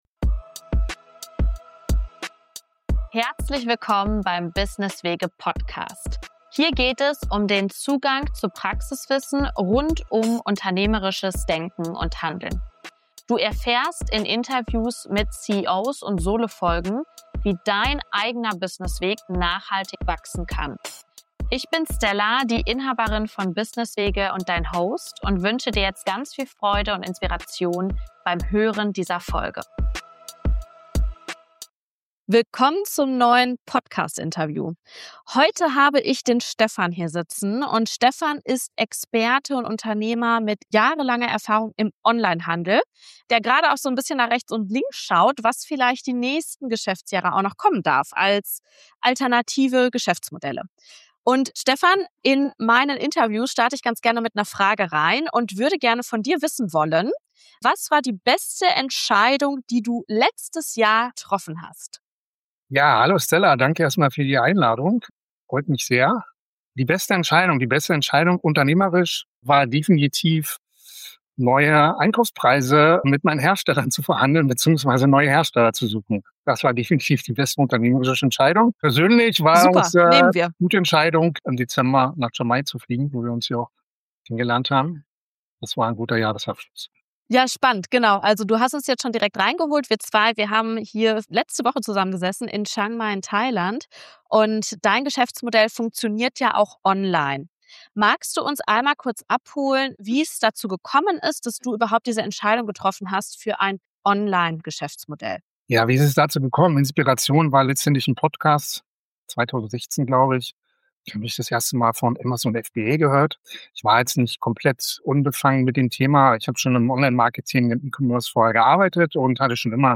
CEO-Talk